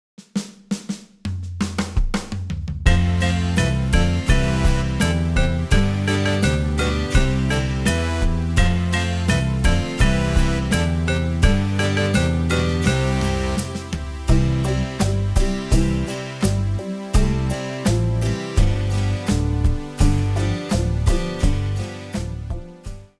Piosenki dla dzieci
Podkład w wysokiej jakości w wersjach mp3 oraz wav